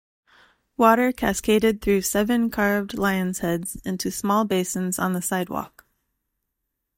Pronounced as (IPA) /ˈbeɪsɪnz/